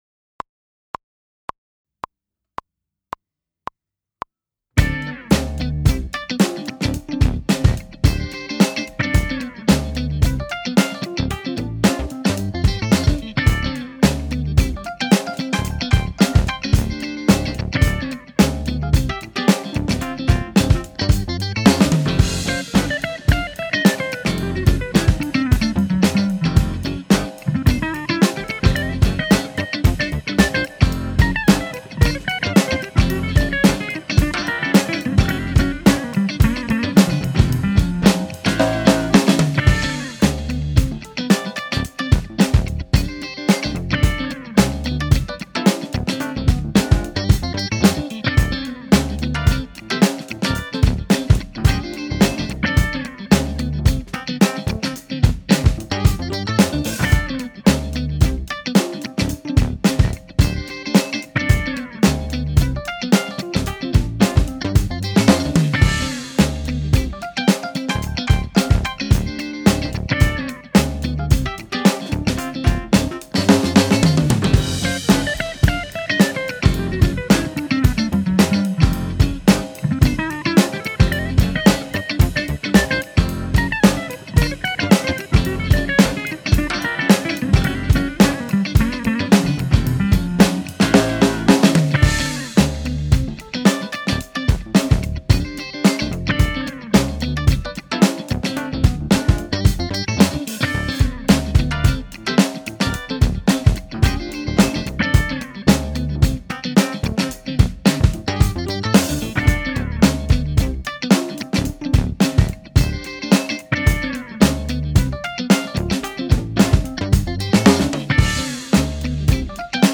sind andere, derzeit: 13'' Paiste Sign. Hi-Hat, K-Custom
Special Dry Ride, 16+14'' AAX Studio Crashes.
Aufnahmen mache ich mittels Tracktion3, Presonus Firestudio,
Behringer Multi-Kompressor, AKG C2000S Overheads & Hihat,
AKG D550 für Bassdrum, D440 für Toms und Snare und 16fach
Bounce (Aufnahme zu nem Playalong)